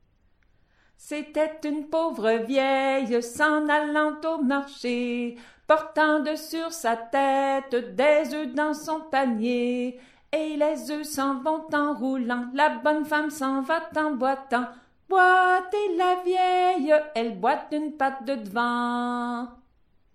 Ronde chantée - C'était une pauvre vieille - Répertoire du patrimoine culturel du Québec
Ronde chantée - C'était une pauvre vieille. Démonstration : Premier couplet et refrain